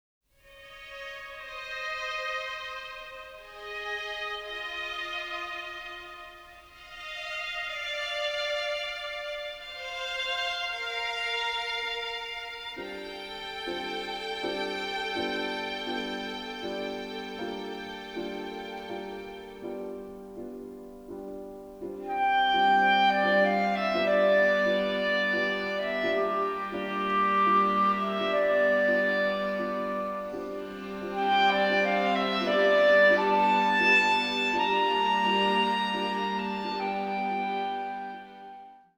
has some jaunty, uplifting moments